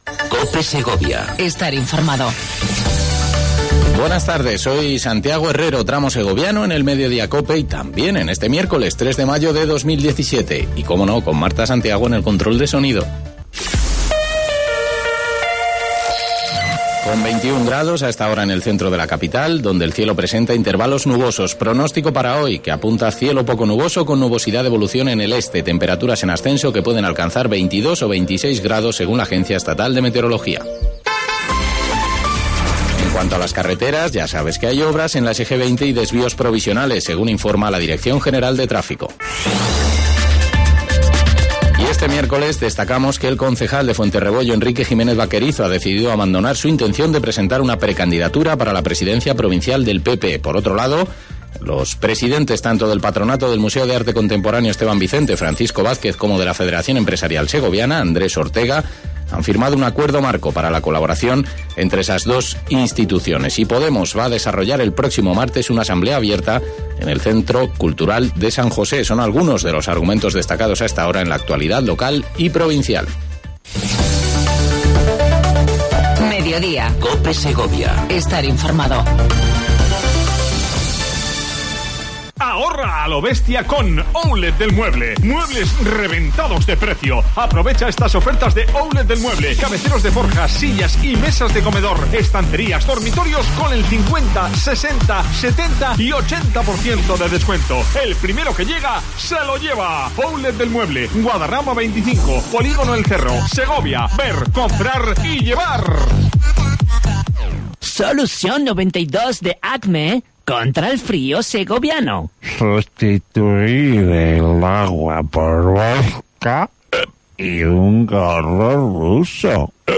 Entrevista con Francisco Vazquez, presidente de la Diputación.